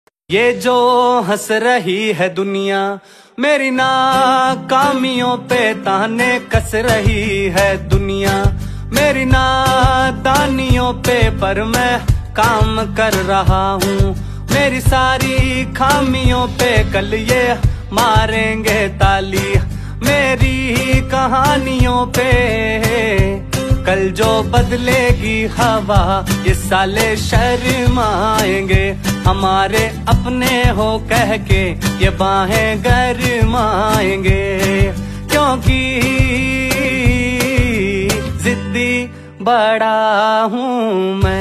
Enjoying The Rain At Cafe Sound Effects Free Download